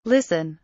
listen kelimesinin anlamı, resimli anlatımı ve sesli okunuşu